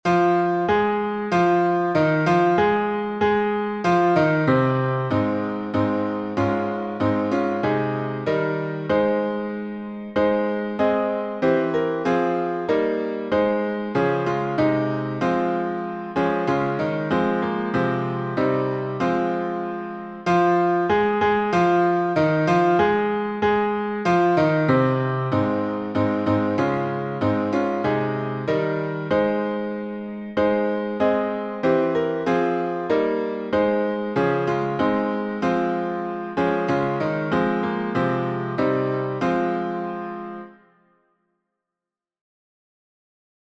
#3021: Come, Ye Sinners, Poor and Needy — alternative chording | Mobile Hymns
Key signature: A flat major (4 flats) Time signature: 4/4